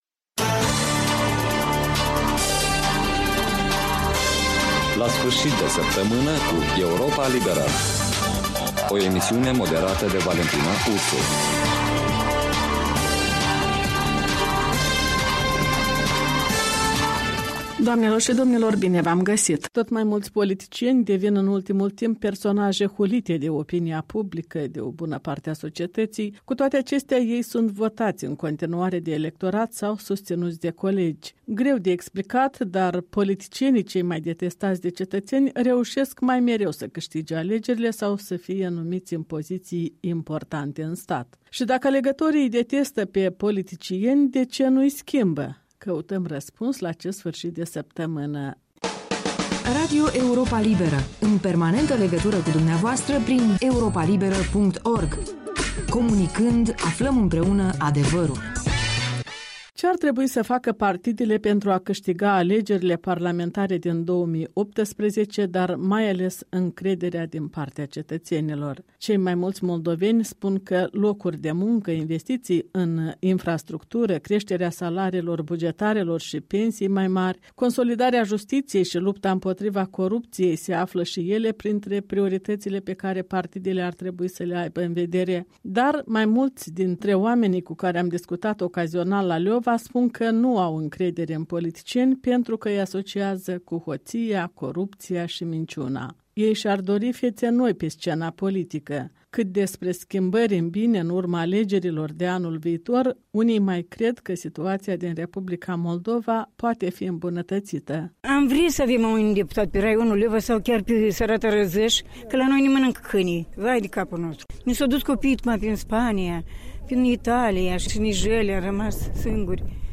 în dialog cu locuitori din Leova